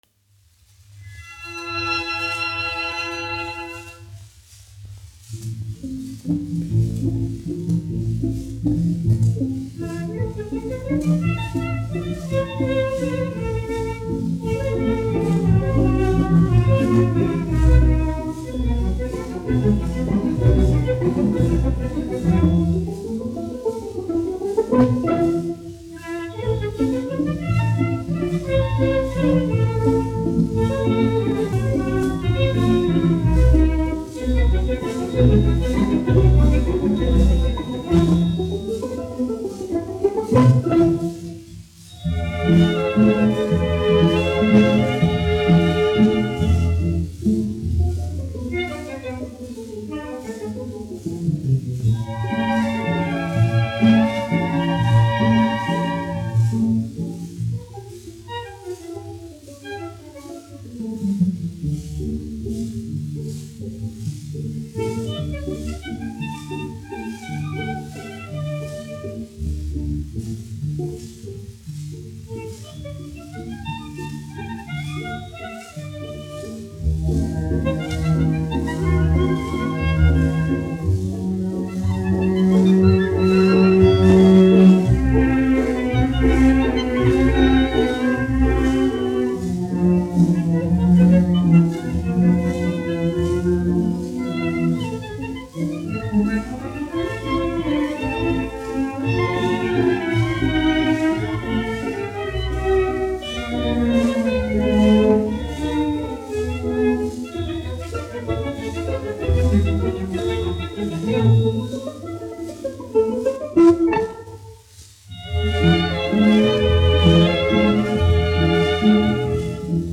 1 skpl. : analogs, 78 apgr/min, mono ; 25 cm
Svītas (orķestris)--Fragmenti
Skaņuplate
Latvijas vēsturiskie šellaka skaņuplašu ieraksti (Kolekcija)